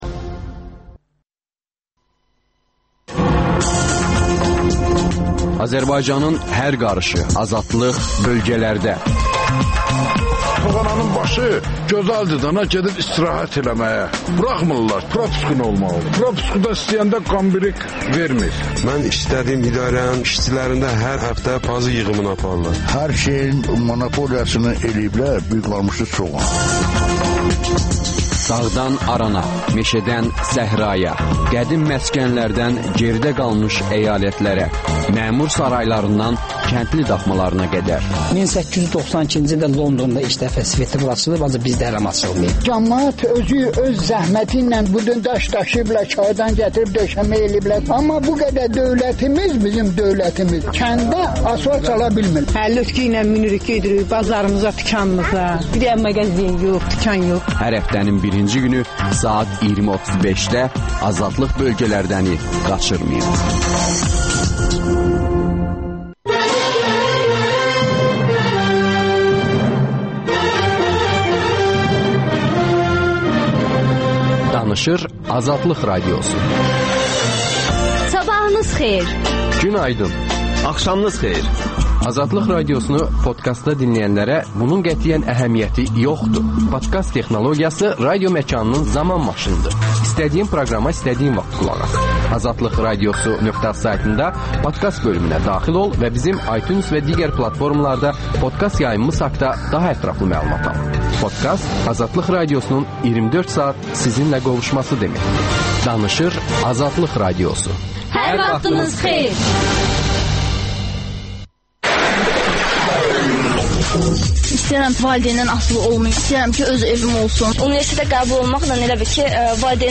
Pen club - Əkrəm Əylisli canlı efirdə!
Ədəbiyyat verilişi